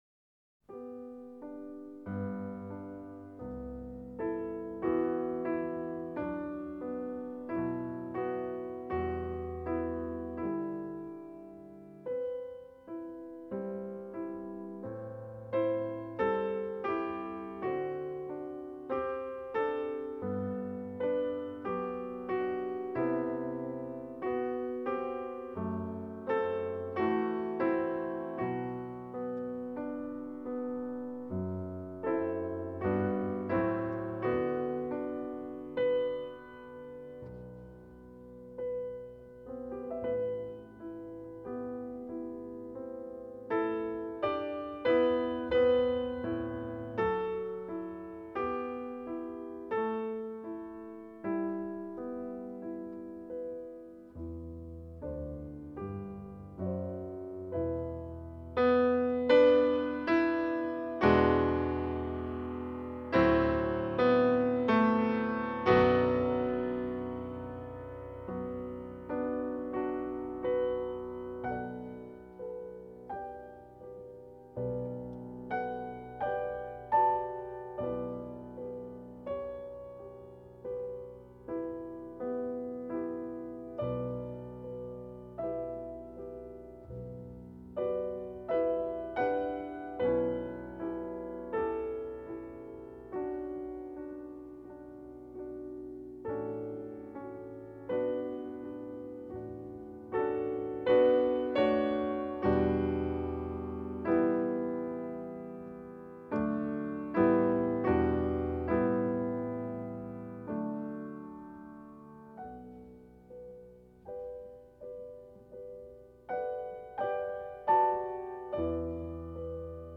Pavana para una infanta difunta (extraído de CD). Grabación realizada para el CD de las Muestra de Jóvenes Intérpretes "Ciudad de Málaga" del año 1996 con motivo de la obtención del 2º Premio de la misma.